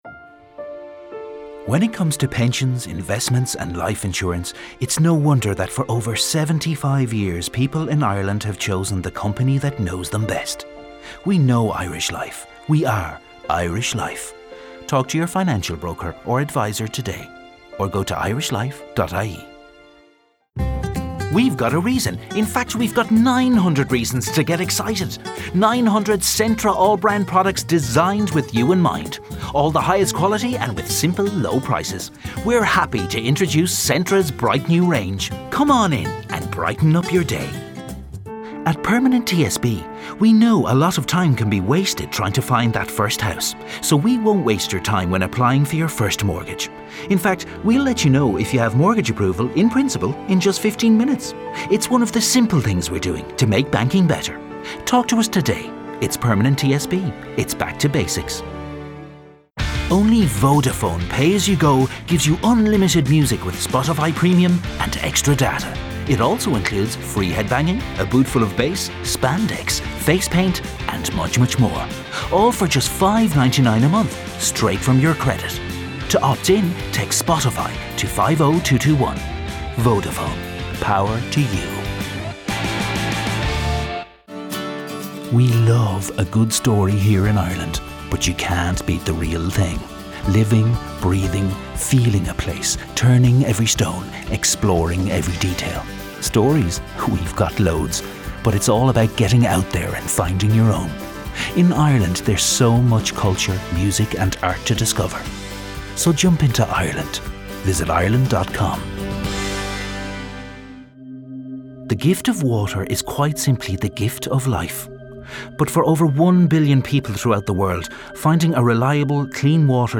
Male
Focusrite Scarlett Solo + condenser mic
40s/50s, 50+
Irish Galway, Irish Neutral